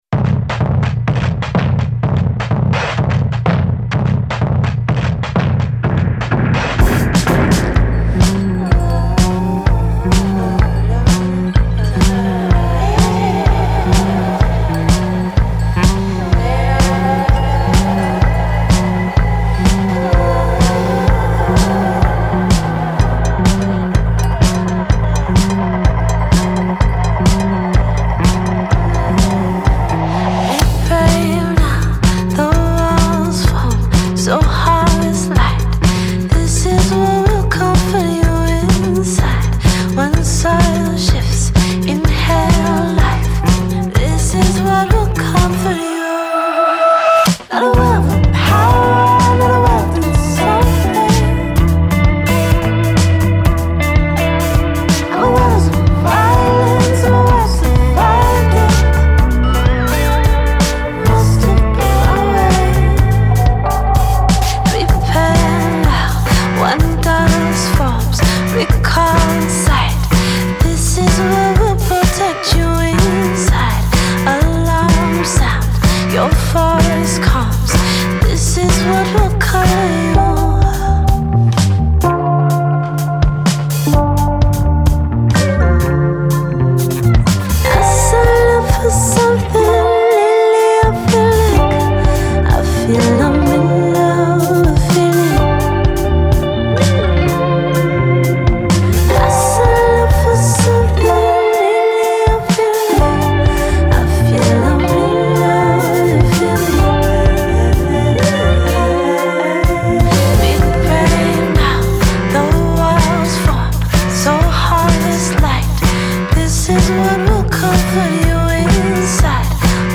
Жанр: Blues.